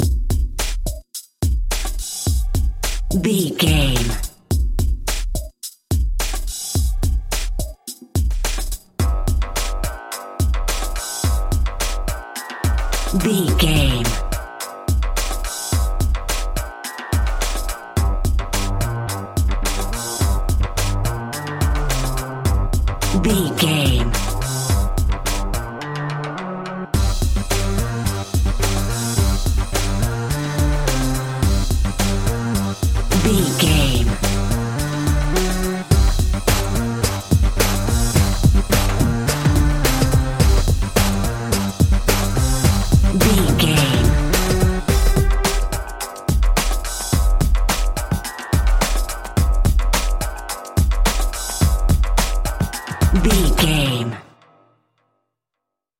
Aeolian/Minor
electronic
techno
trance
drone
glitch
synth lead
synth bass